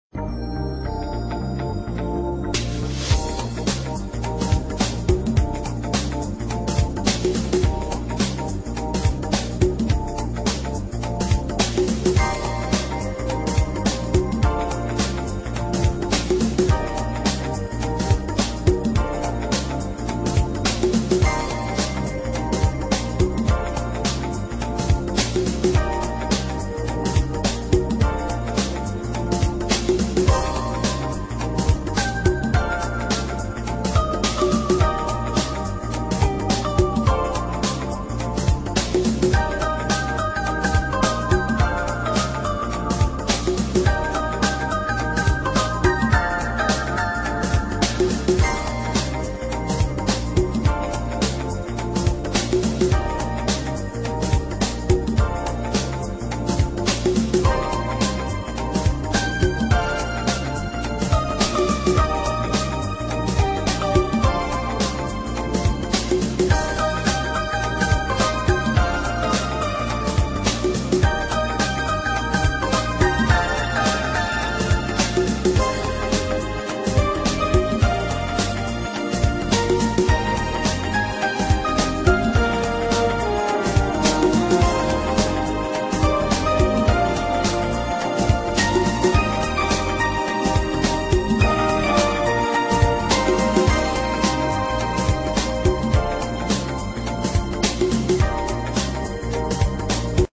为了先给大家心里有个数儿，我讲明：这是个纯电子混声的音乐，旋律很好听很现代很恬静，有未来感（应该肯定不是陈美的，更加不是什么柴可夫斯基肖邦的等等，也不太像是阿米克的，音乐气息是当代气息），没有演唱者。